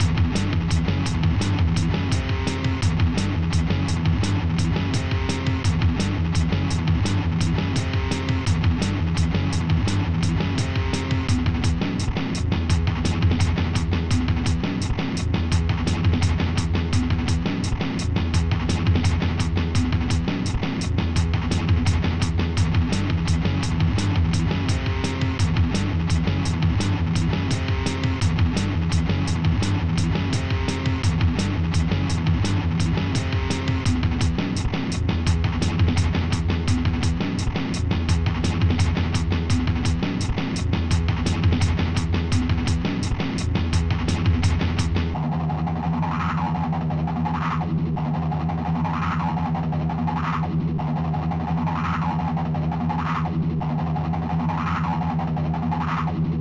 mod (ProTracker MOD (6CHN))